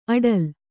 ♪ aḍal